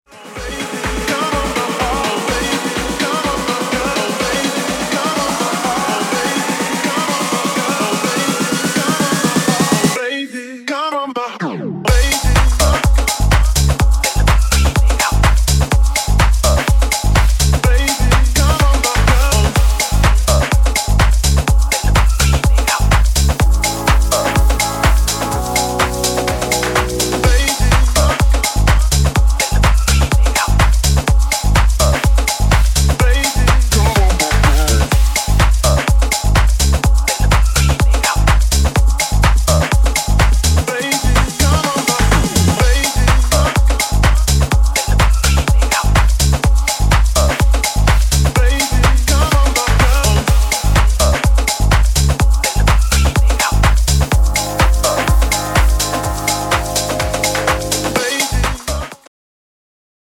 House, Tech House y Techno más bailable